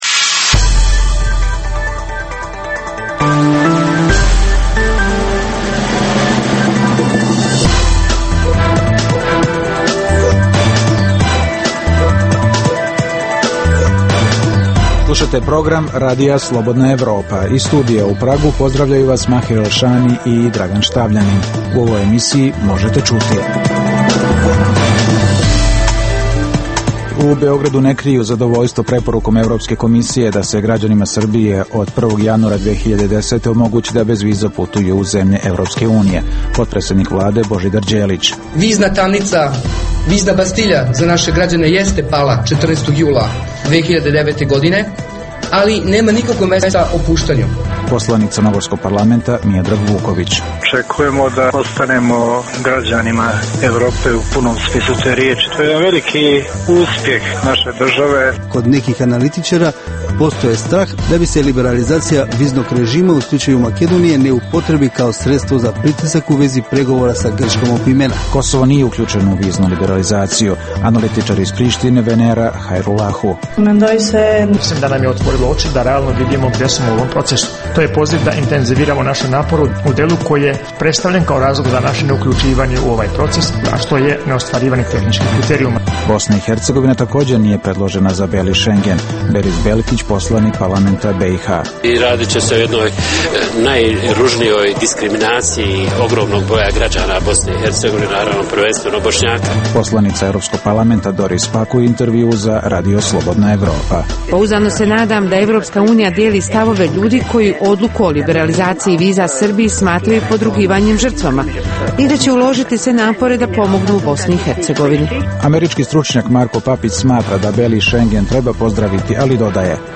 Nadbiskup vrhbosanski kardinal Vinko Puljić u intervjuu za naš radio govori o položaju Hrvata u BiH.